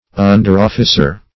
Underofficer \Un"der*of`fi*cer\